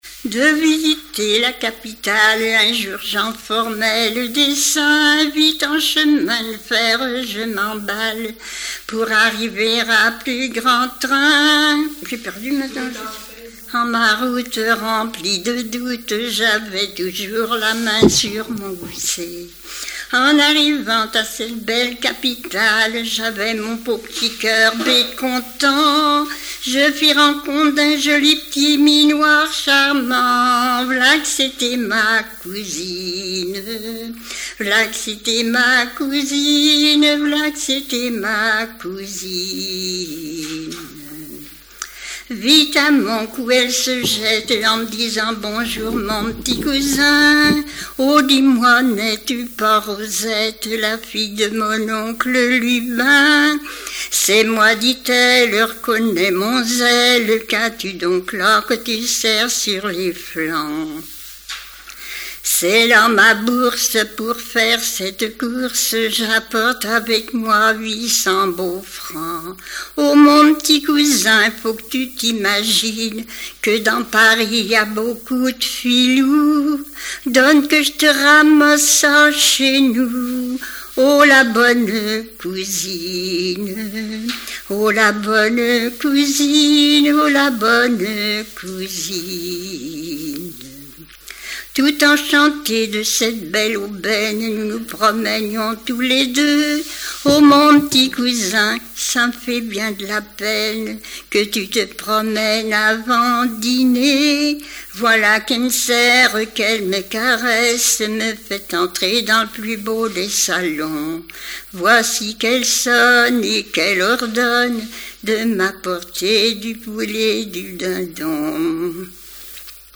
music-hall
Genre strophique
Répertoire de chansons populaires et traditionnelles
Pièce musicale inédite